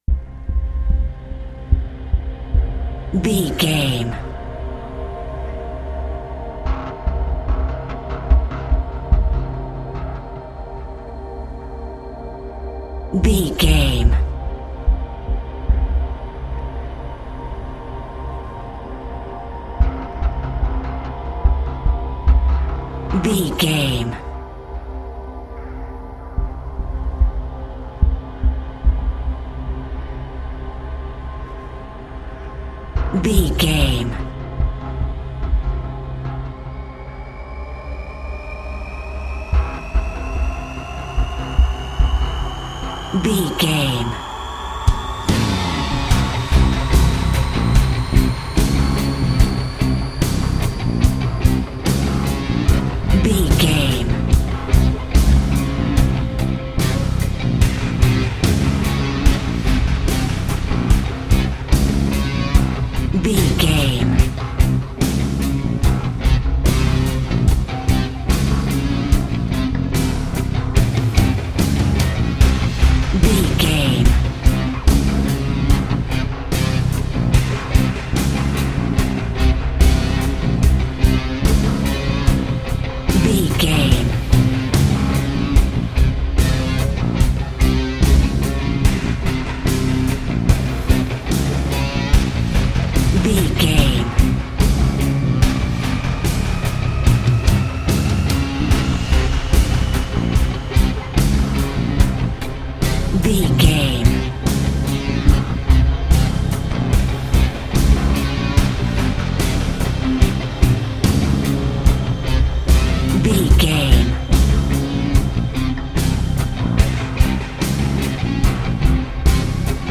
Thriller
Aeolian/Minor
synthesiser